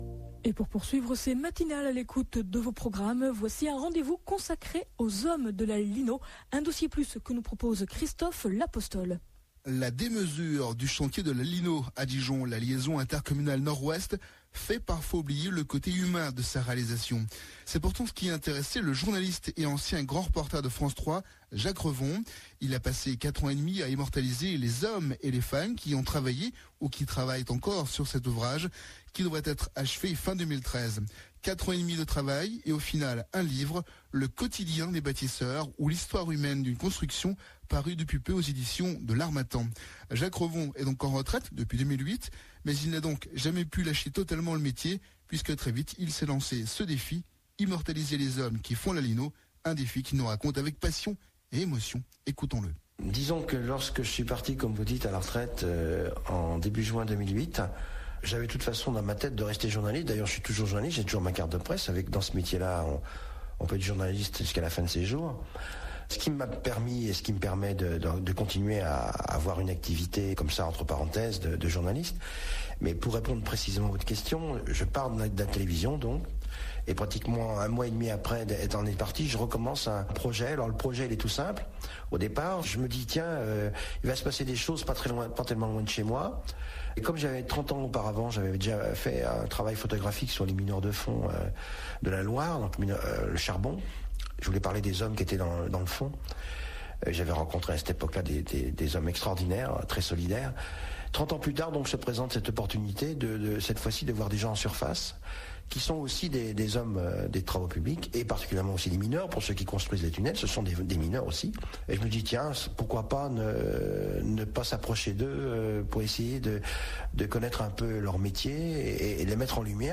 Émission Radio
Interview de l'auteur sur radio RCF Bourgogne